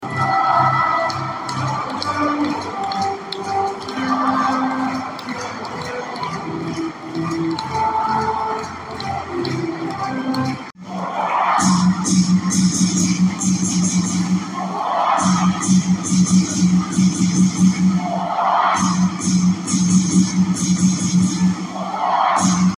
Już przed pierwszym gwizdkiem kibice głośno dopingowali biało-czerwonych.
stadion-dzwiek.mp3